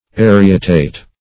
Search Result for " arietate" : The Collaborative International Dictionary of English v.0.48: Arietate \Ar"i*e*tate\, v. i. [L. arietatus, p. p. of arietare, fr. aries ram.] To butt, as a ram.